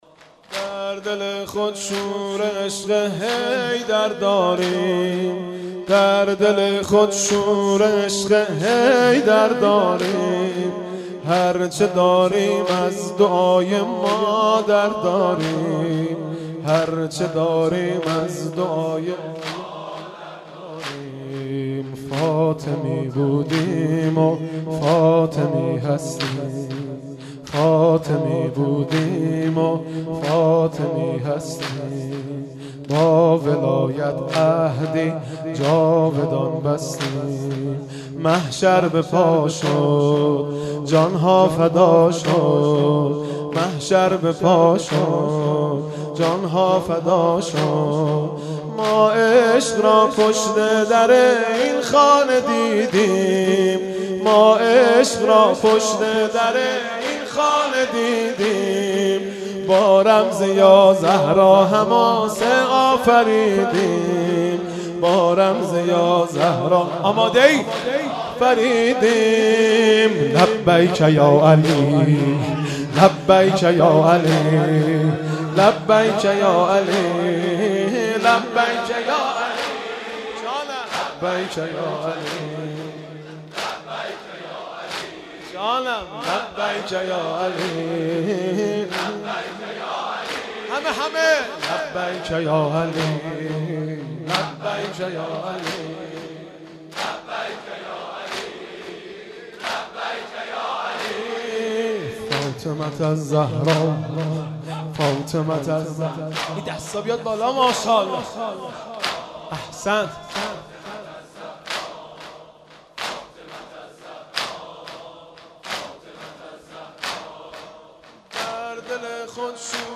عزای فاطمیه 5 بهمن 97 - شور حاج مهدی رسولی، در دل خود شور عشق حیدر داریم
اقامه عزای فاطمیه جامعه ایمانی مشعر بهمن 97